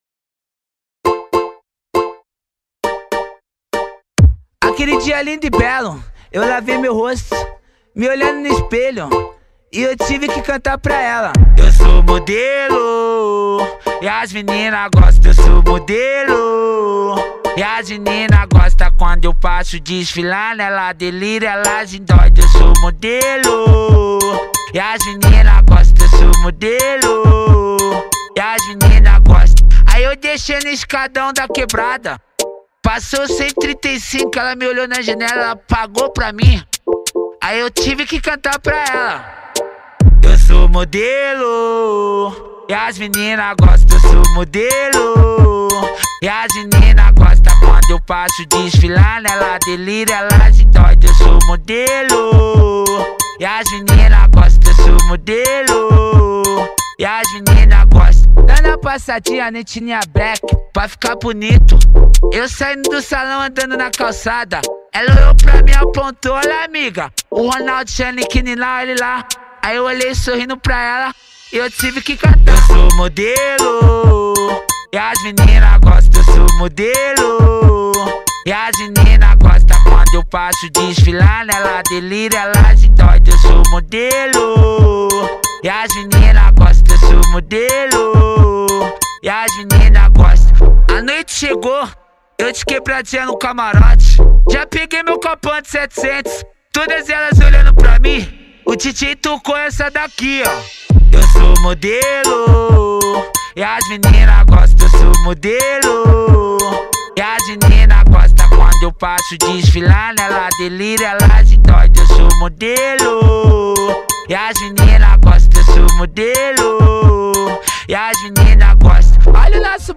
Bass Boosted